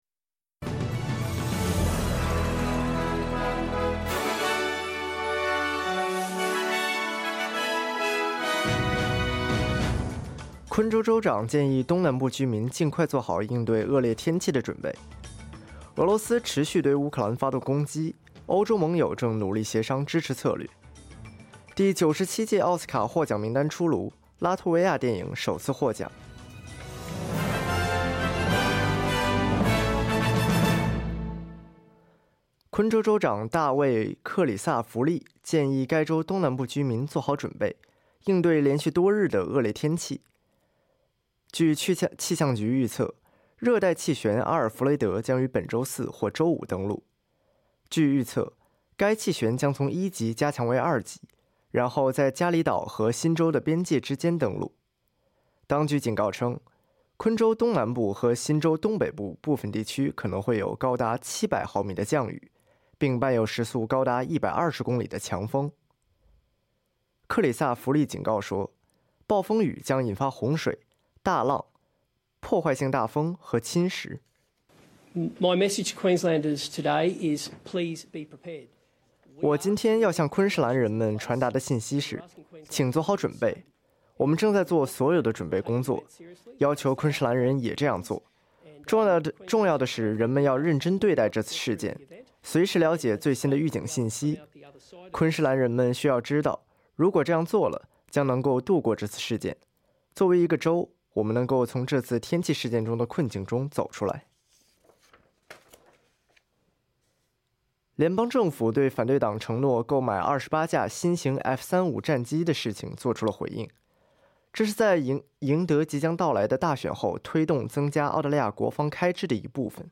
SBS早新闻（2025年3月4日）